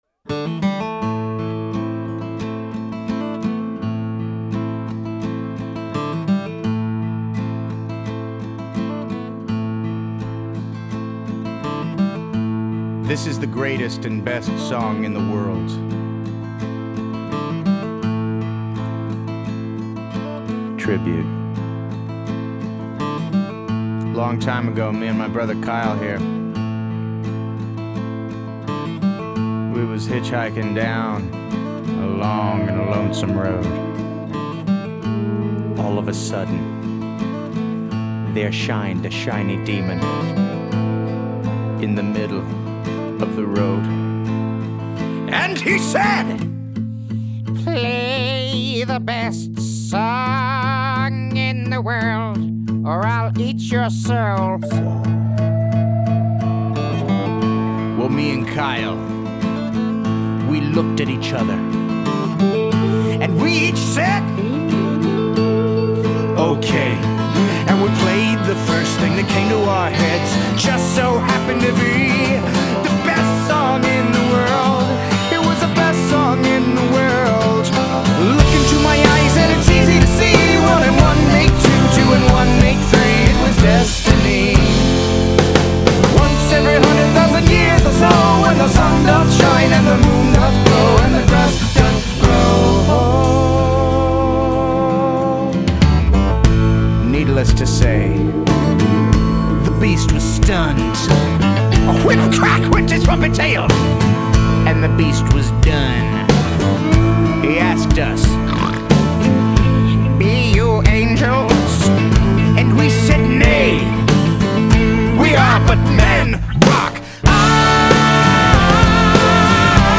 dance/electronic
Drum & bass